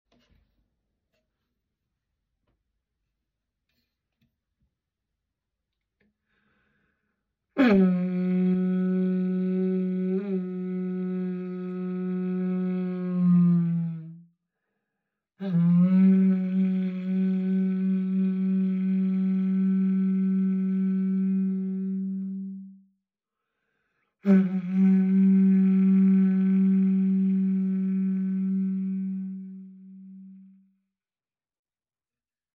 This is a Conch, or sound effects free download
The god Triton is often portrayed blowing a large seashell horn.